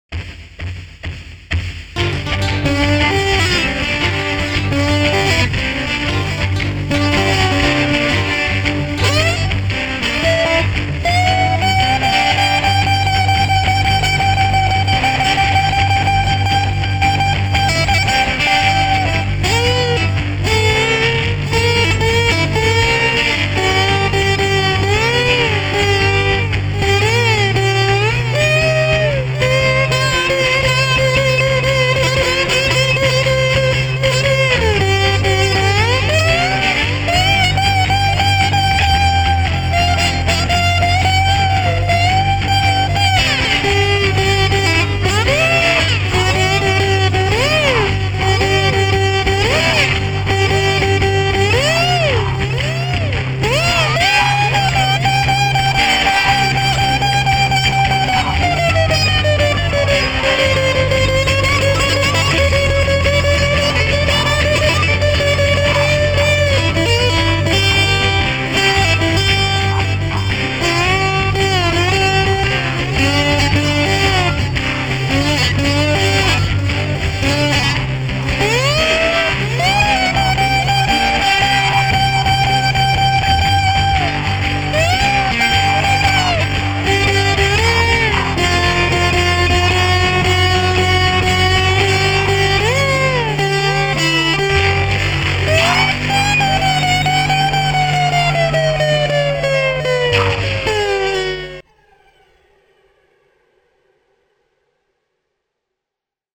Blues genre route 66. 2004